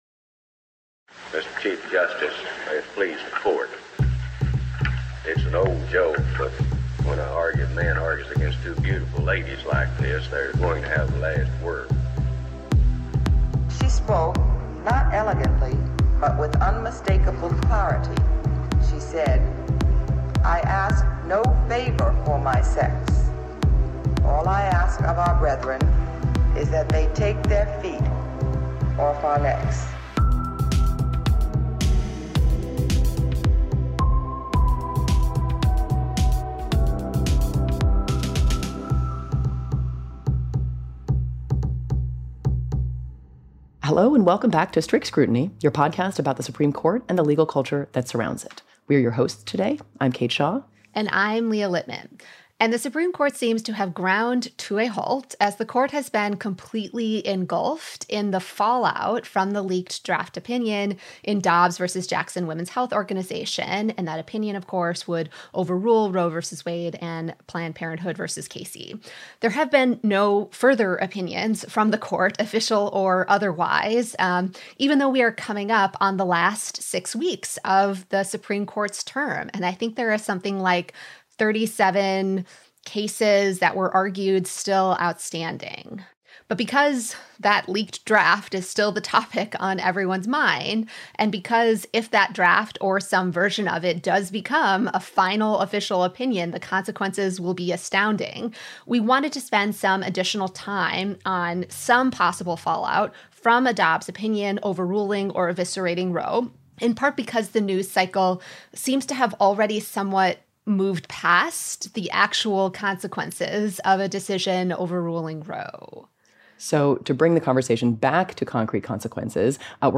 They interview two people with insight on what we can expect in a post-Roe world.